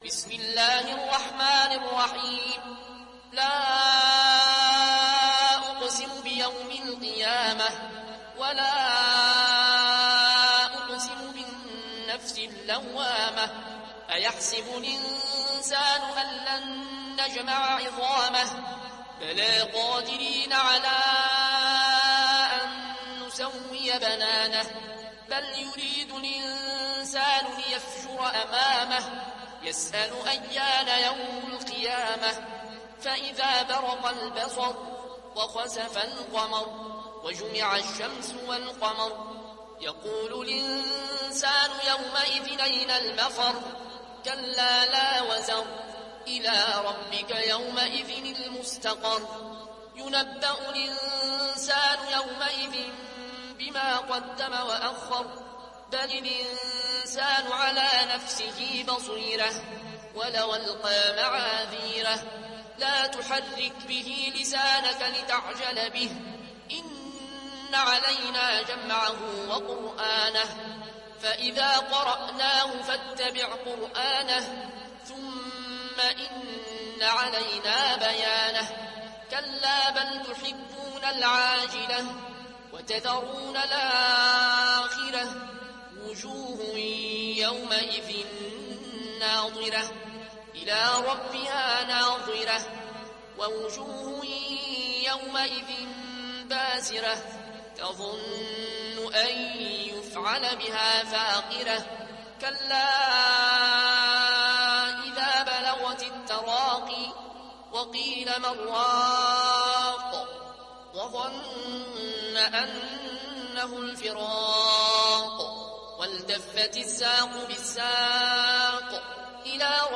ورش عن نافع